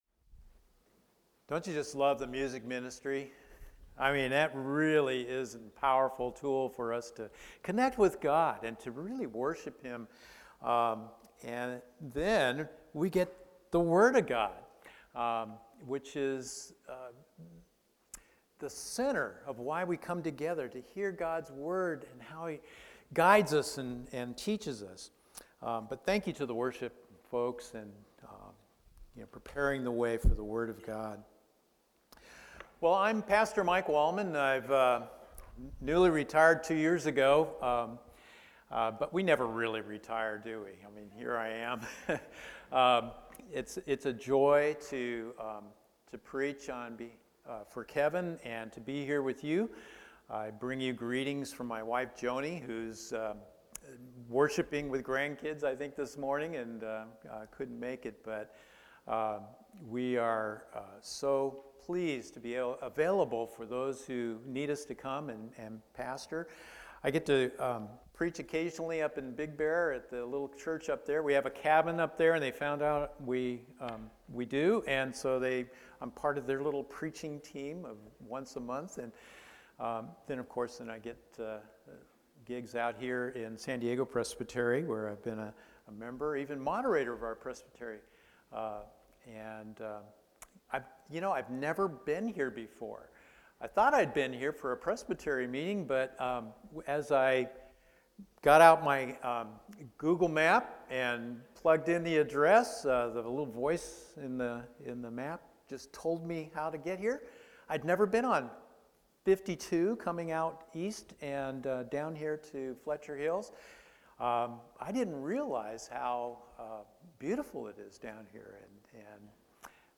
I AM... THE WAY, THE TRUTH, AND THE LIFE | Fletcher Hills Presbyterian Church